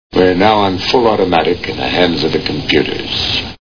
Planet of the Apes Movie Sound Bites